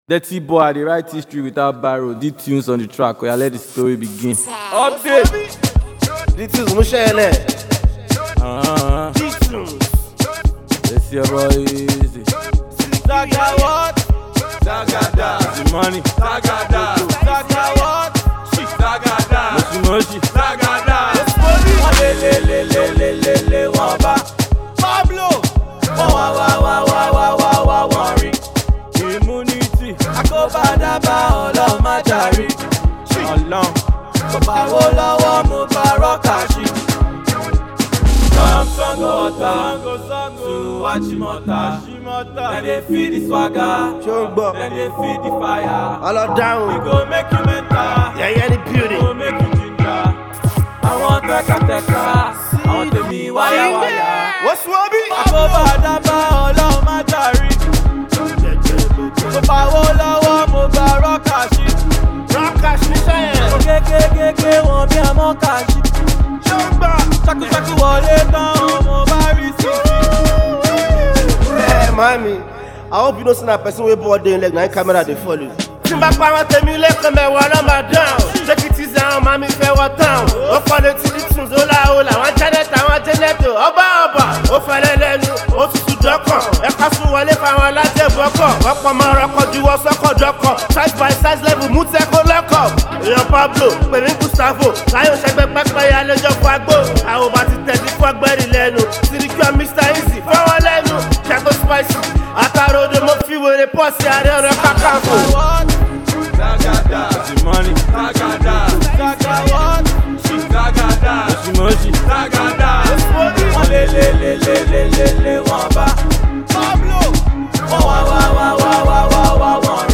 indigenous rapper
street-tailored tune